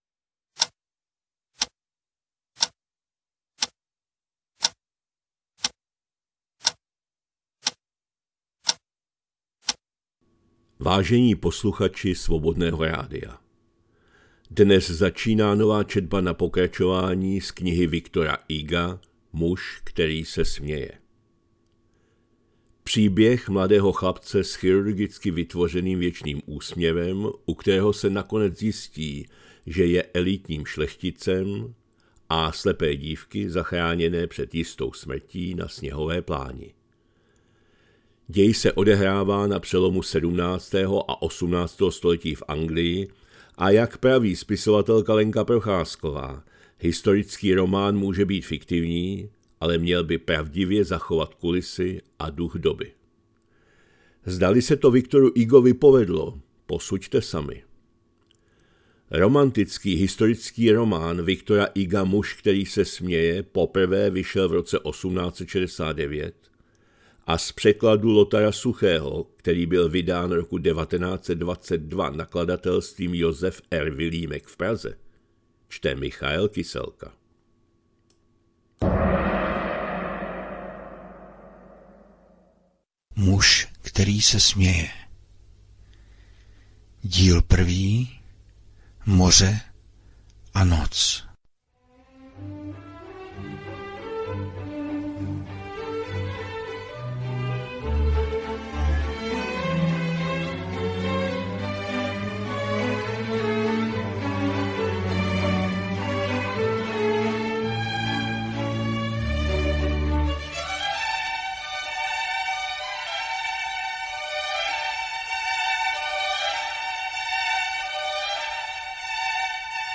2025-02-10 - Studio Kalich - Muž který se směje, V. Hugo, část 1., četba na pokračování